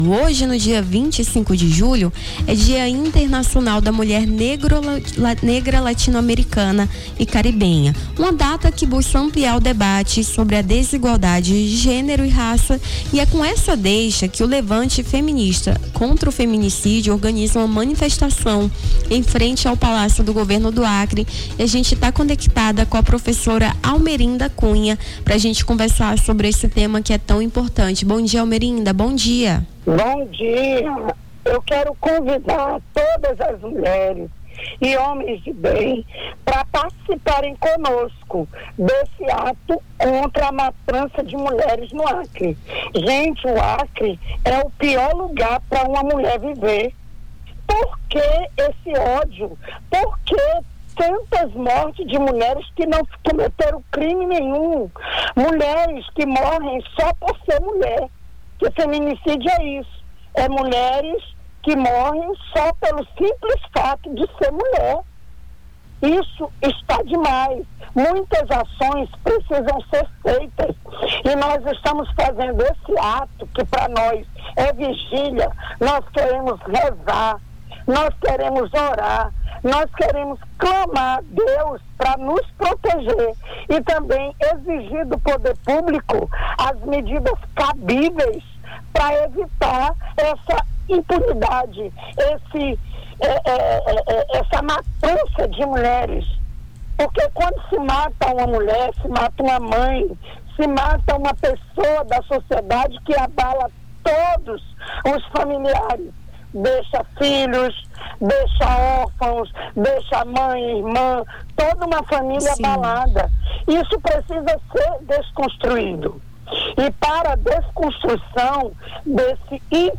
Nome do Artista - CENSURA - ENTREVISTA MANIFESTO PAREM DE NOS MATAR (23-07-25).mp3